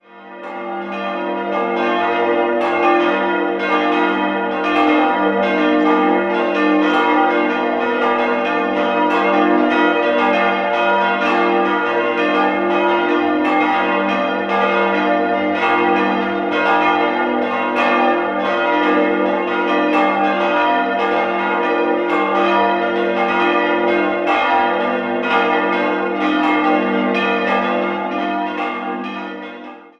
5-stimmiges Geläute: g'-b'-c''-d''-f'' Die kleinste Glocke wurde um das Jahr 1500 in Nürnberg gegossen, die nächste 1540 von Hans Turnknopf in Regensburg und die große im Jahr 1522 von Hans Stain in Amberg.